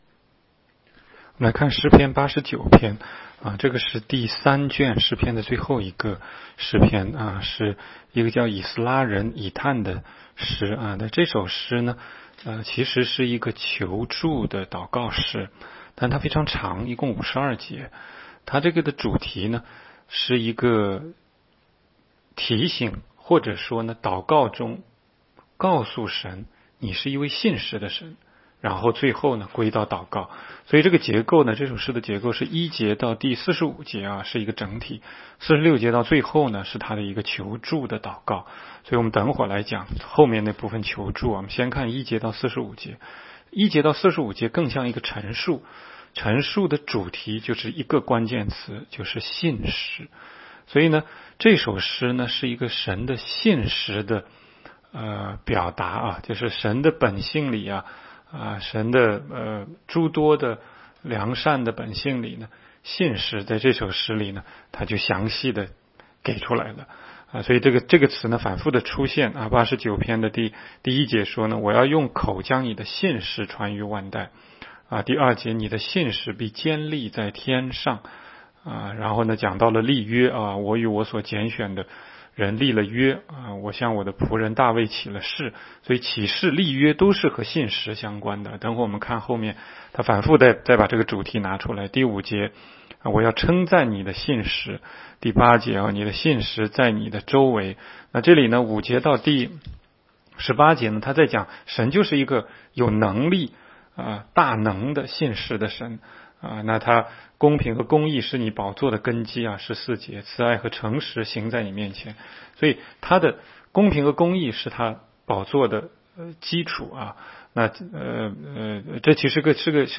16街讲道录音 - 每日读经-《诗篇》89章